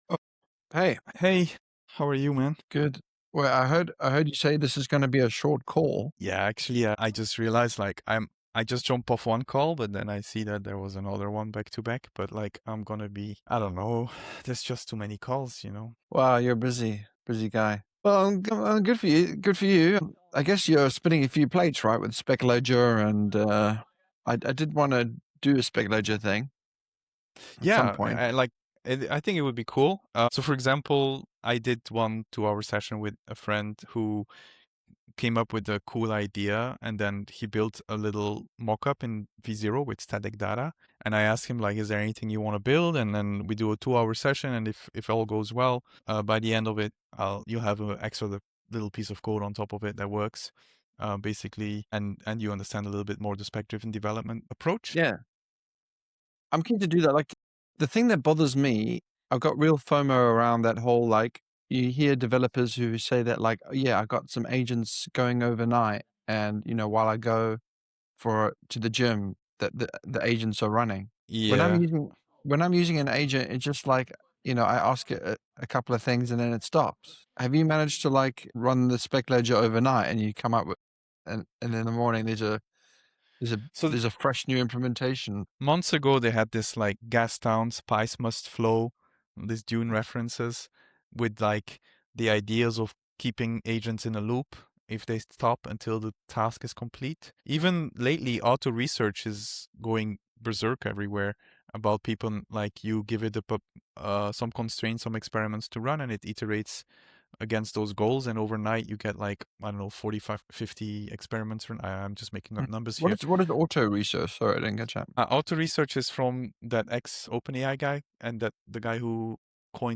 A technical conversation between two engineers covers hands-on AI agent work, tooling decisions, multi-account cloud operations, and how AI changes tedious platform tasks.
The tone is pragmatic: what worked, what diverged from plan, and which manual chores are now realistic to automate.